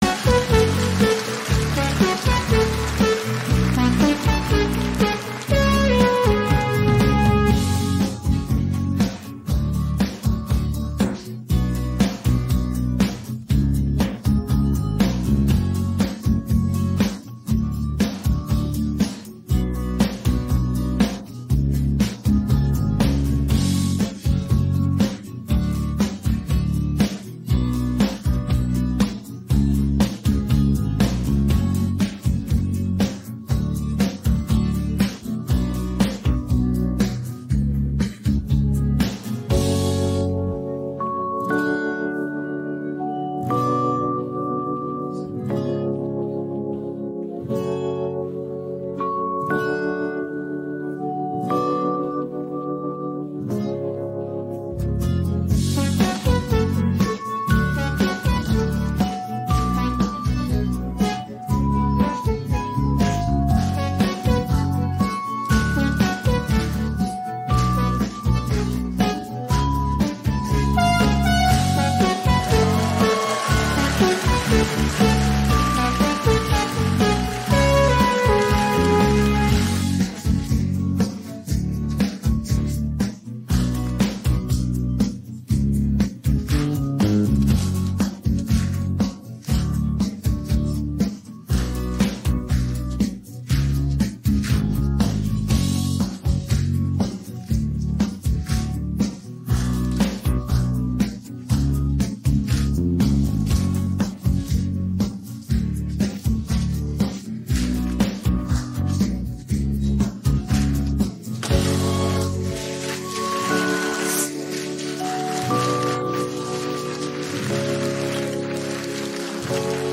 Українські хіти караоке